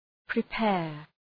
Προφορά
{prı’peər}